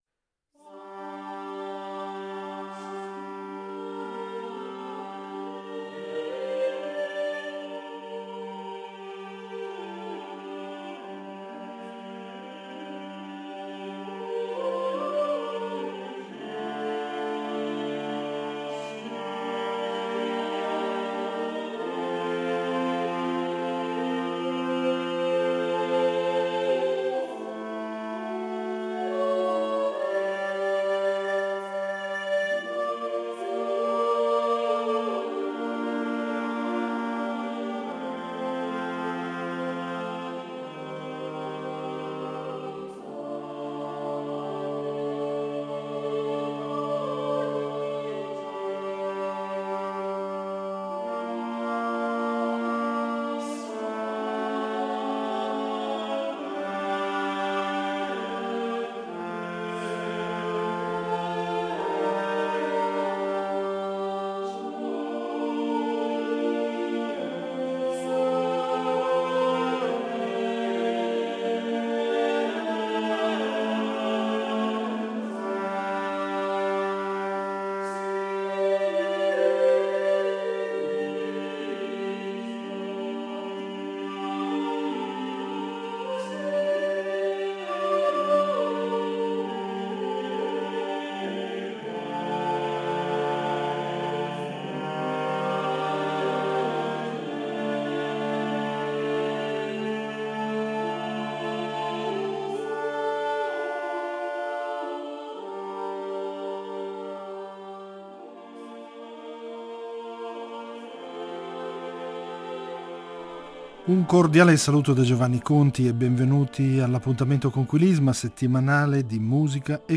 Le loro incisioni faranno da colonna sonora alla puntata odierna.